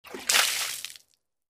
На этой странице собраны разнообразные звуки воды: журчание ручья, шум прибоя, капли дождя и плеск водопада.
Плеск волны